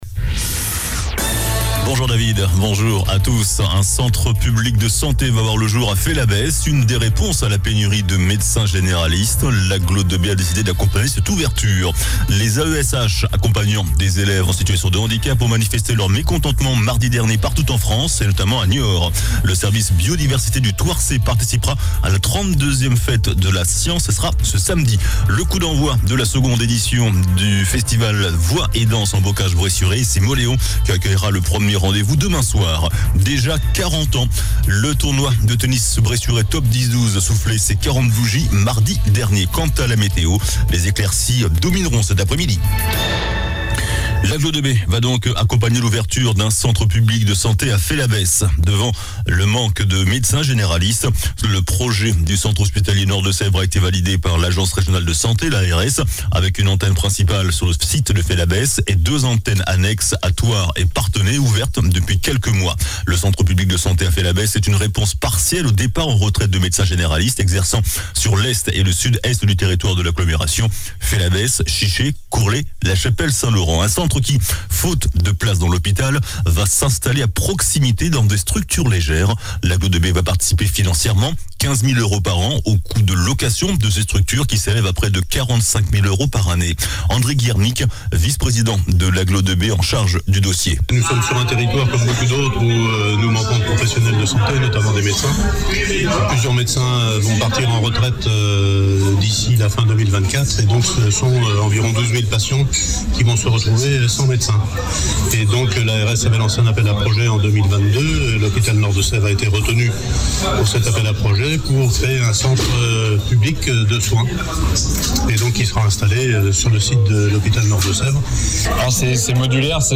JOURNAL DU JEUDI 05 OCTOBRE ( MIDI )